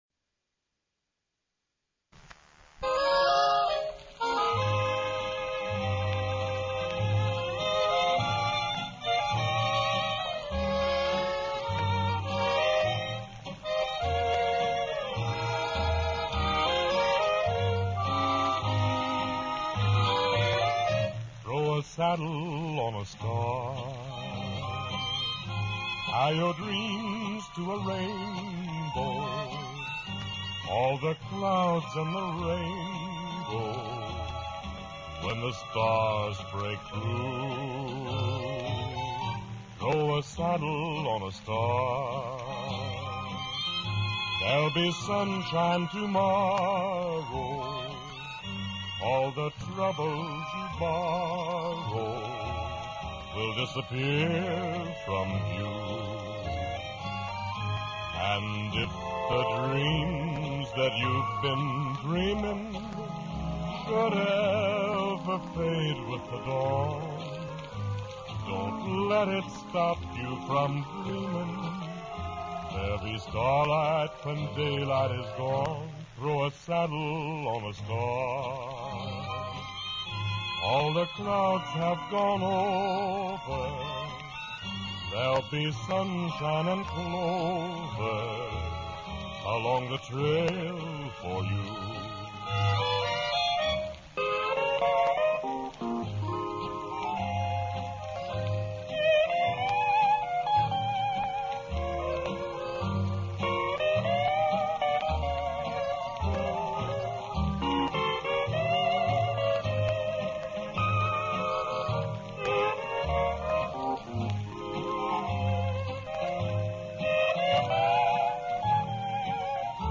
steel guitar solo